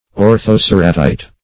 Search Result for " orthoceratite" : The Collaborative International Dictionary of English v.0.48: Orthoceratite \Or`tho*cer"a*tite\, n. [Ortho- + Gr.
orthoceratite.mp3